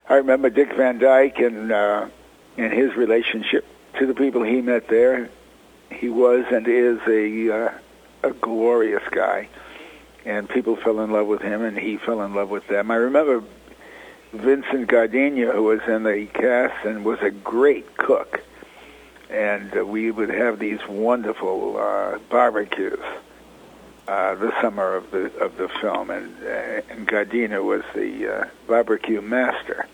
Lear returned to Iowa in 2014, and in a Radio Iowa interview, recalled how “Cold Turkey” came out the same year his show, “All in the Family” premiered on C-B-S. The ground-breaking sitcom dealt with controversial issues previously ignored in comedies.